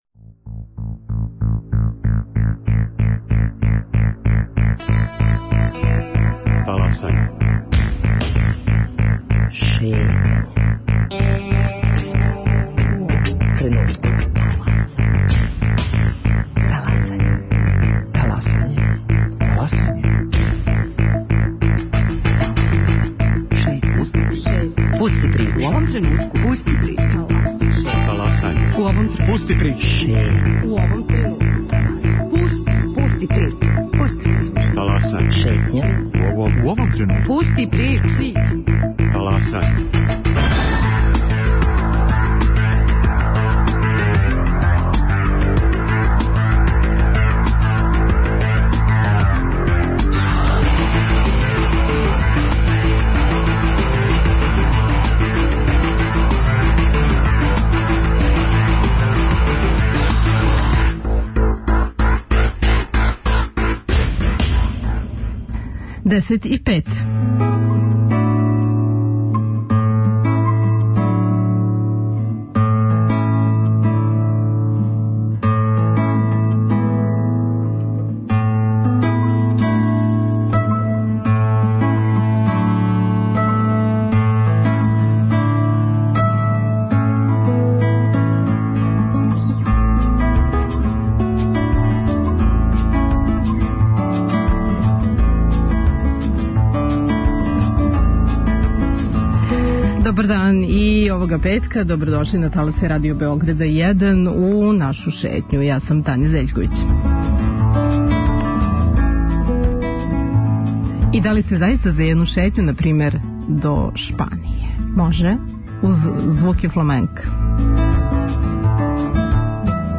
Састоји се од певања, играња и музичке пратње на гитари.